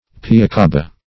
Search Result for " piacaba" : The Collaborative International Dictionary of English v.0.48: Piacaba \Pi*a[,c]"a*ba\, n. See Piassava .